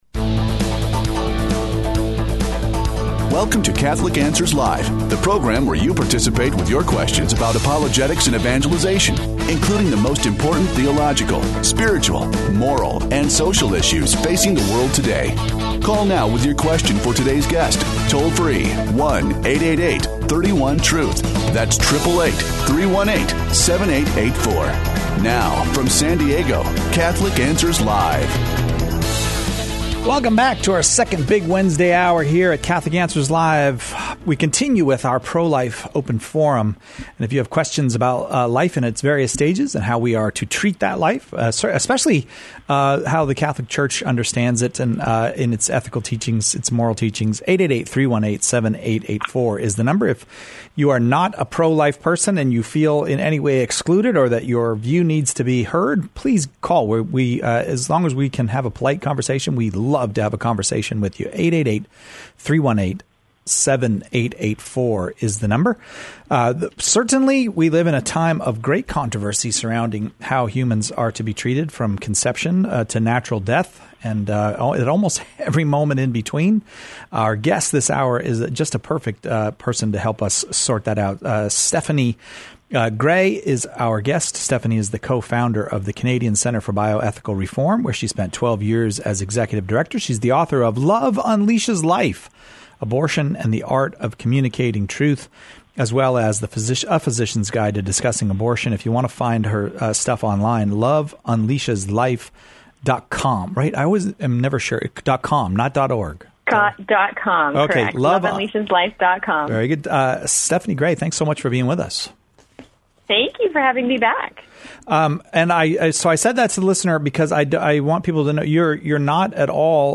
Callers share their questions about pro-life issues from conception to natural death. Questions Covered: 13:44 - What is the connection between Planned Parenthood and the Girl Scouts? 16:27 - I hear pro-abortion people admit that a fetus cannot survive in such and such circumstances.